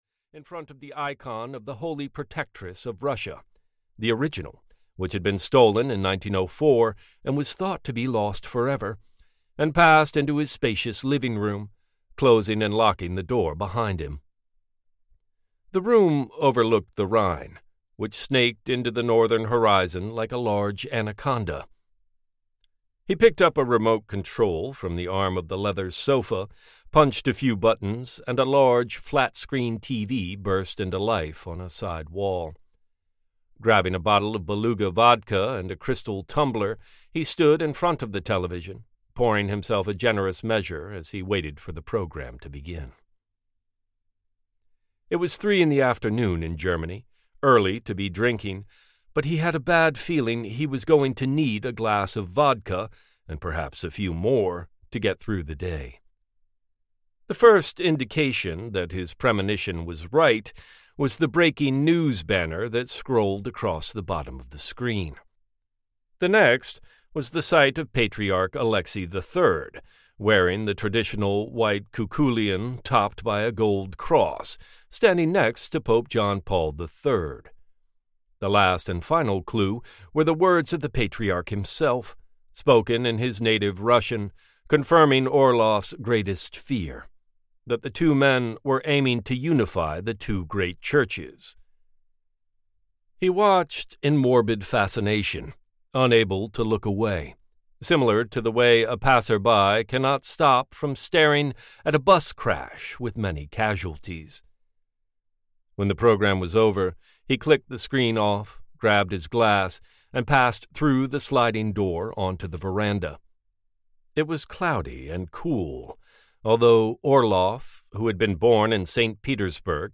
The Vatican Secret (EN) audiokniha
Ukázka z knihy